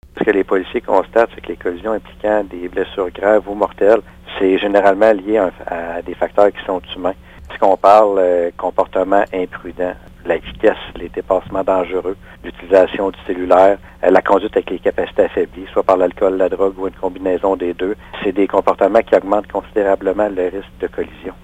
Le policier précise que les causes d’accidents graves restent aussi les mêmes d’une année à l’autre.